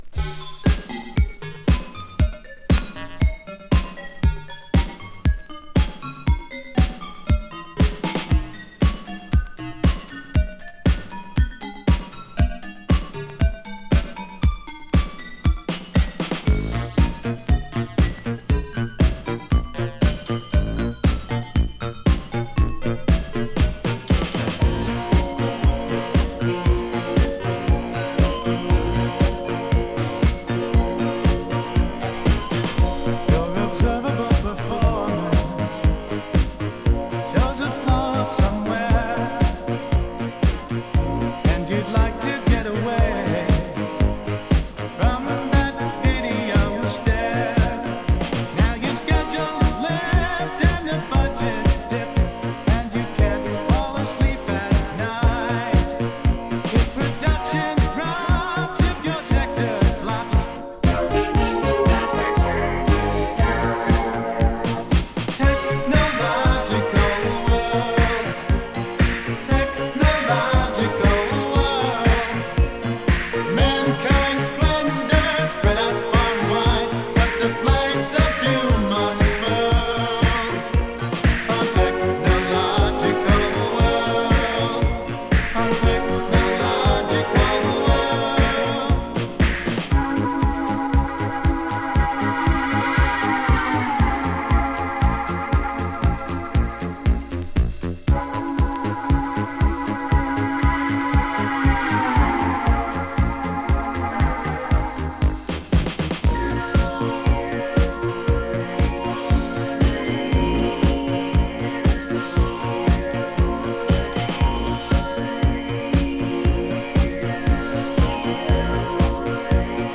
Deep Disco
【HI-NRG】【DISCO】